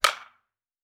Foley Sports / Baseball - Cricket / Bat Hit Wood Long.wav
Bat Hit Wood Long.wav